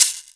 Perc 4 [ shaker ].wav